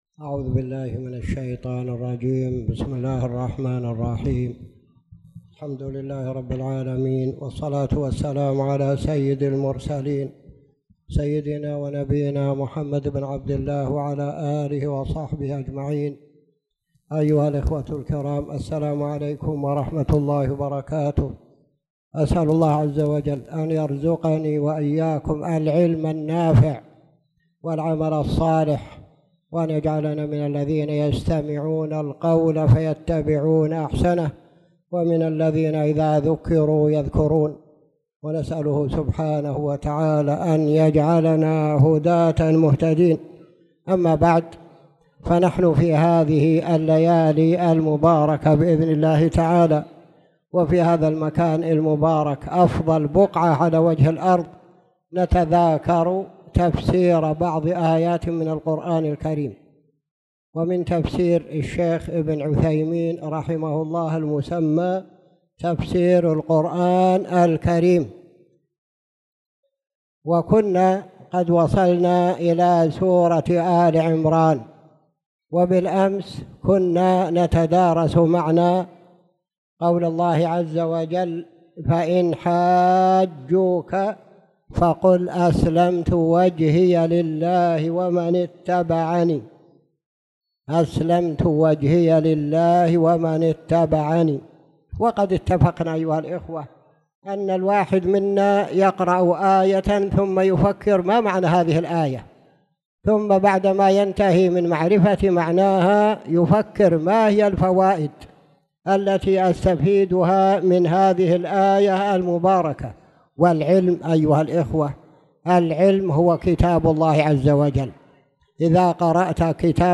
تاريخ النشر ٢٥ ربيع الثاني ١٤٣٨ هـ المكان: المسجد الحرام الشيخ